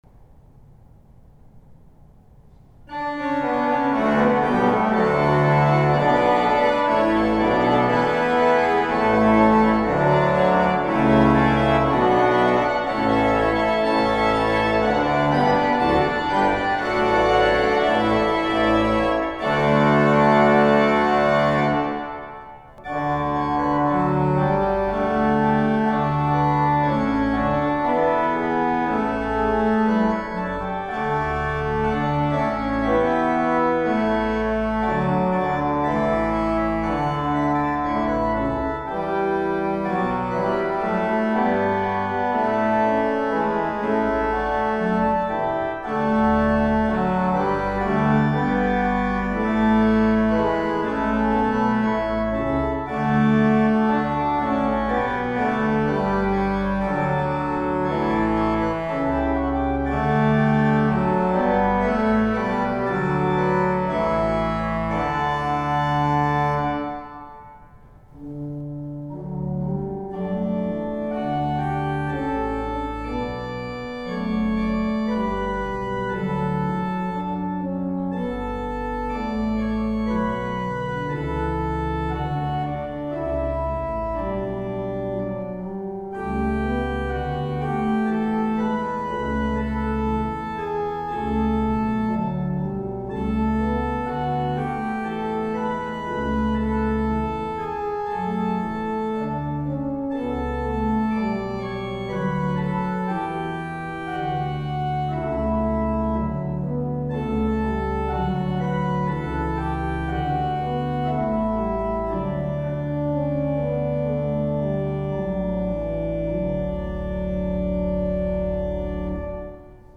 Partituren en opnamen van de psalmen Tenzij anders vermeld zijn de opnamen gemaakt in 2018 - 2022 met een Tascom DR-05 Linear PCM-recorder.
GKO Voorspel - Tenorzetting - Canon - Sopraanzetting - Naspel 2:59 106 pdf GKO Voorspel 1 - Voorspel 2 - Sopraanzetting - Voorspel 2 + Sopraanzetting (tempo Klaas Bolt) - Trio 5:57 107 pdf GKO Voorspel - Sopraanzetting 1:53 110 pdf HKO Voorspel - Sopraanzetting - Altzetting - Naspel 1:54 113 pdf GKO Voorspel - Sopraanzetting - Alt/tenorzetting 2:13 117 pdf HKO Intonatie - Sopraanzetting - Naspel 1:22 117d pdf OKB Koorzetting Taizé - 4 tegenstemmen 2:05 118 pdf GKO Voorspel 1 (zie Psalm 66) - Samenzang Zetting 1 - Samenzang Zetting 2 Live opname voorjaar 2022 2:37 119 pdf HKO Voorspel 1 - Sopraanzetting - Voorspel 2 - Tenorzetting - Basse de Cromorne 3:14 119a pdf HKO Orgelkoraal - Bicinium - Sopraanzetting 2:49 121 pdf GKO Voorspel 2 - Sopraanzetting - Voorspel 1 - Tenorzetting 2:09 122 pdf GKO Voorspel 1 - Sopraanzetting - Voorspel 2 - Canon 3:40 124 pdf GKO Voorspel 1 - Voorspel 2 -Tenorzetting - Sopraanzetting 3:32 128 pdf GKO Voorspel - Sopraanzetting - Tenorzetting 2:10 130 pdf HKO Kort voorspel - Sopraanzetting - Tenorzetting - Altzetting 2:58 130a pdf HKO Voorspel - Koraal 1:40 130c pdf YP Intro - Couplet - Couplet 1:15 133 pdf HKO Voorspel - Sopraanzetting 1:28 134 pdf VVH Intonatie - Sopraanzetting - Voorspel - Variatie 1 - Variatie 2 - Variatie 3a 2:30 135 pdf GKO Voorspel - Sopraanzetting 1:03 136 pdf HKO Voorspel 1 - Sopraanzetting - Voorspel 2 - Altzetting 1:52 138 pdf GKO Voorspel - Zwevende cantus firmus - Collectespel 1:03 139 pdf